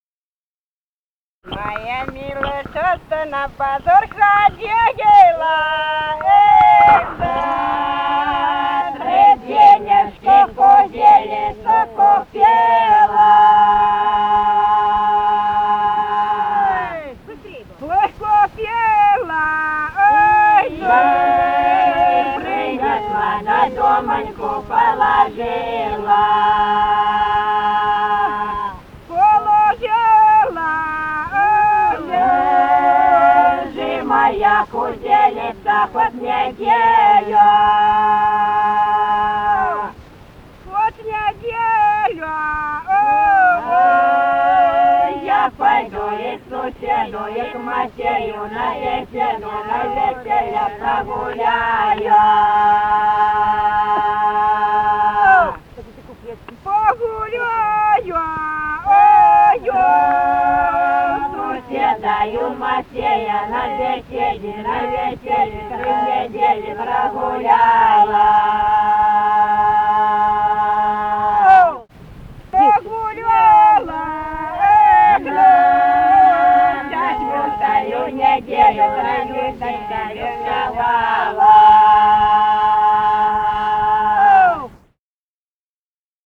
и группа женщин. Записали участники экспедиции